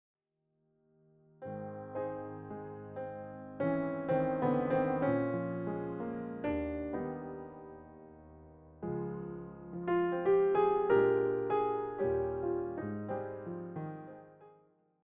With its steady, expressive piano style